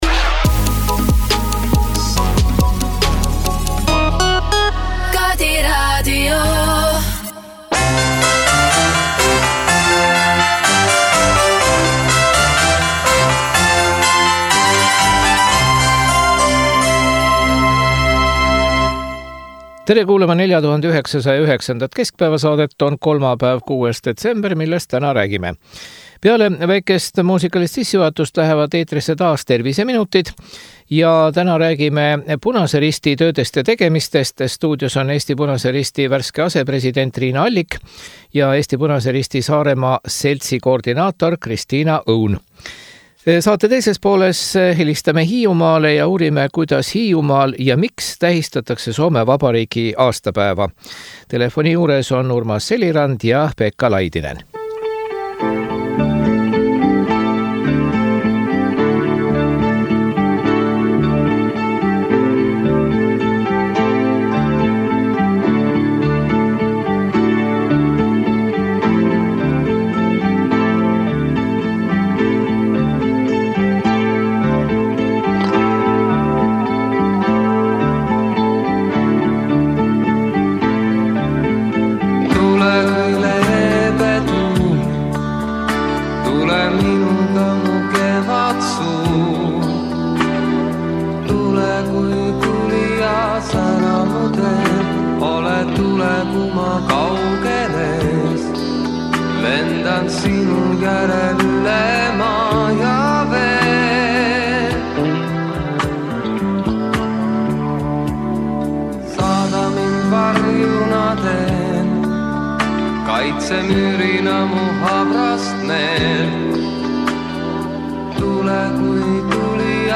Terviseminutites räägime Punase Risti tööst. Stuudios
Kuidas tähistatakse Hiiumaal Soome vabariigi aastapäeva? Telefonil